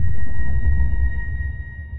sonarTailSuitClose2.ogg